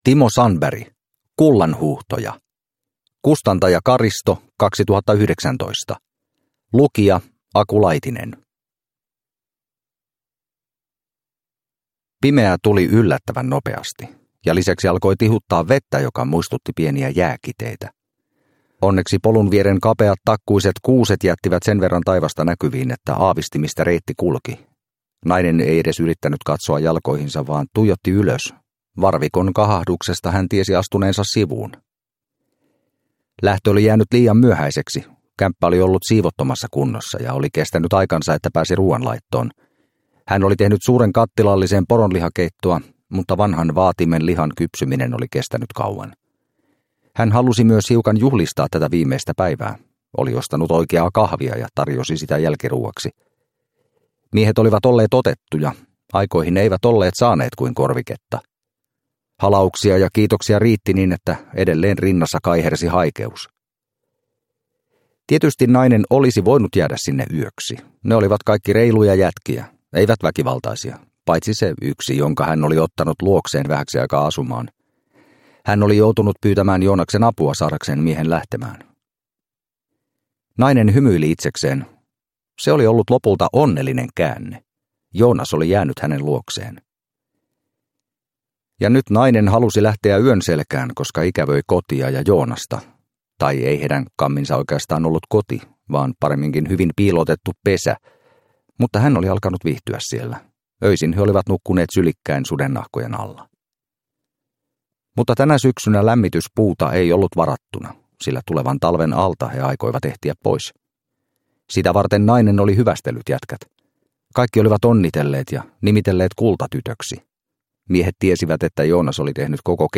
Kullanhuuhtoja – Ljudbok – Laddas ner